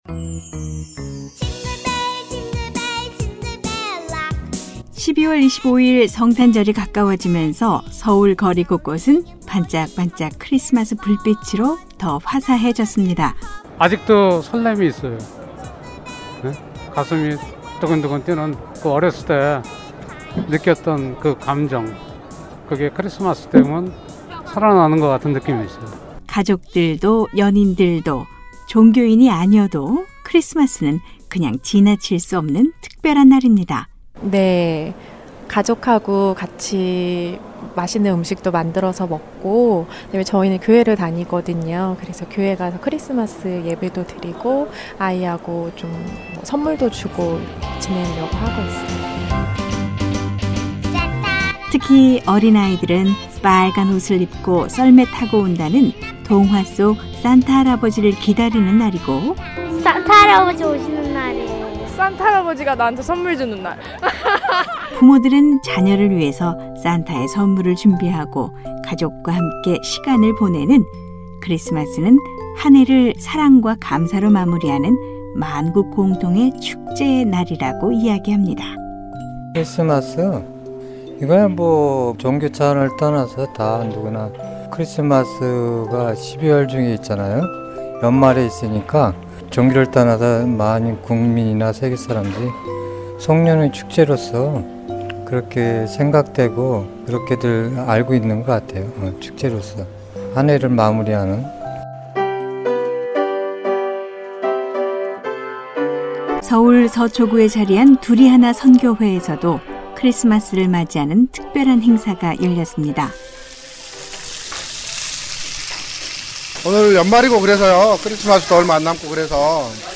한국의 이모저모를 전해드리는 ‘안녕하세요. 서울입니다’ 순서 오늘은 크리스마스-성탄절을 즐기는 한국사람들의 목소리를 전해드립니다. 교회나 성당을 다니는 종교인이 아니어도 크리스마스-성탄절에는 가족과 연인들끼리 선물도 주고 받고, 맛있는 음식을 나누며 즐거운 하루를 보낸다고 하는데요.